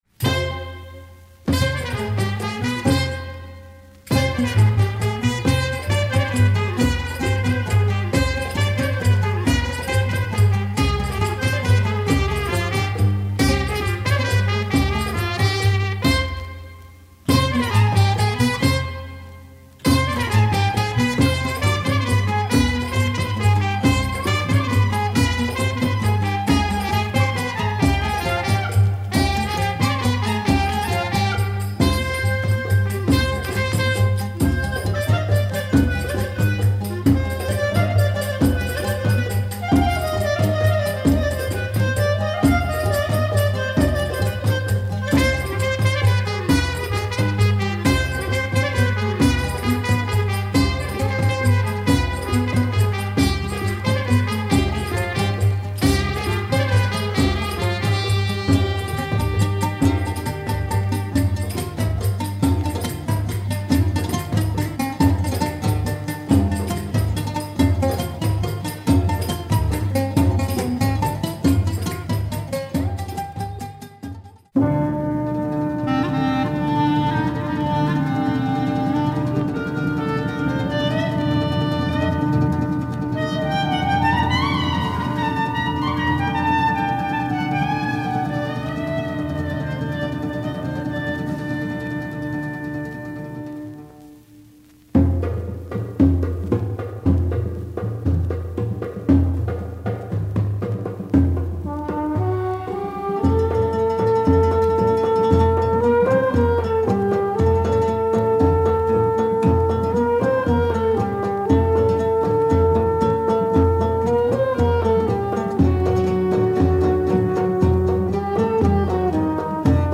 Fantastic Egyptian soundalike jazz !
clarinet